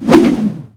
tentakle.ogg